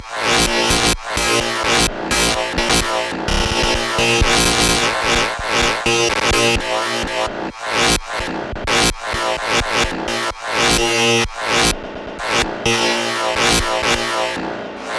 描述：伤害者被砍伤了，我前段时间做了鼓。